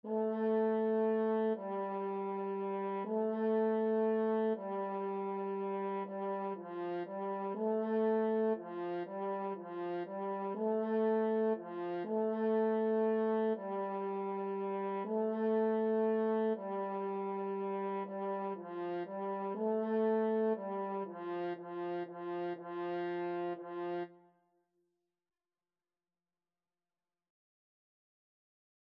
3/4 (View more 3/4 Music)
F4-A4
French Horn  (View more Beginners French Horn Music)
Classical (View more Classical French Horn Music)